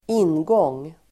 Uttal: [²'in:gång:]